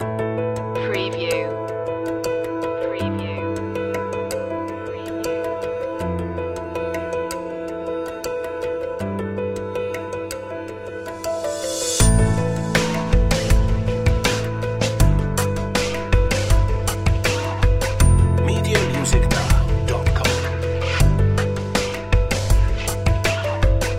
Dark reflective royalty free soundtrack music.